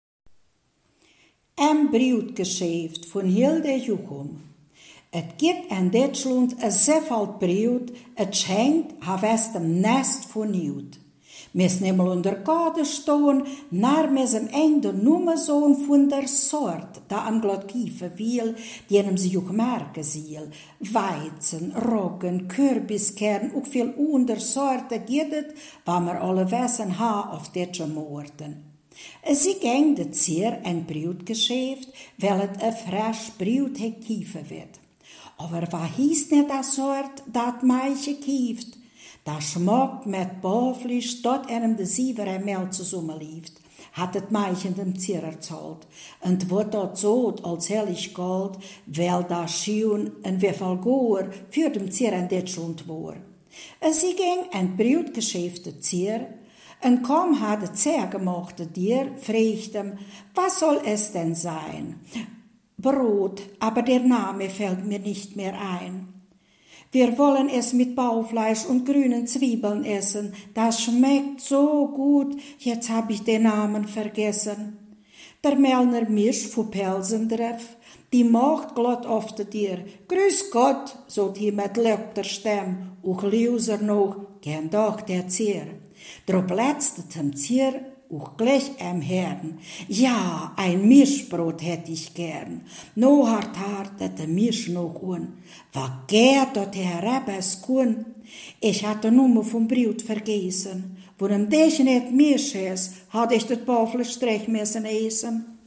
Ortsmundart: Frauendorf